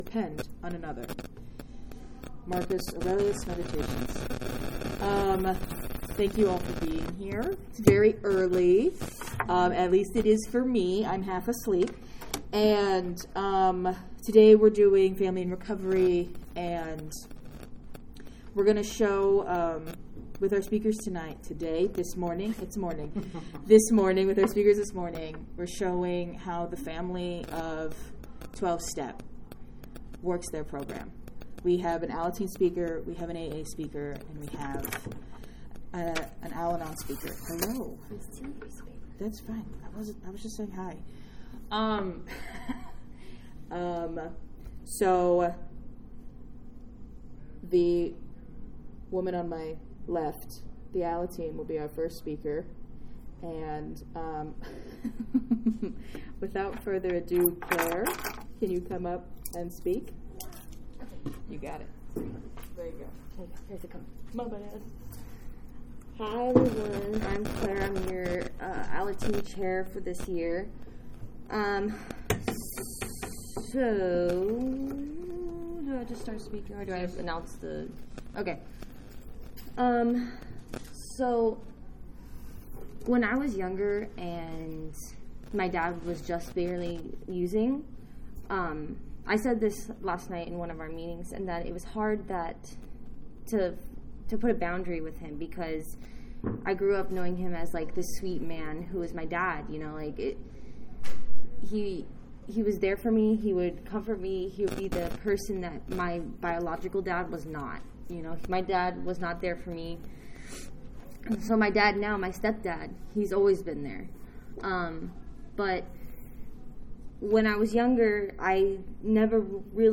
47th Annual San Fernando Valley AA Convention - Al-Anon Family Meeting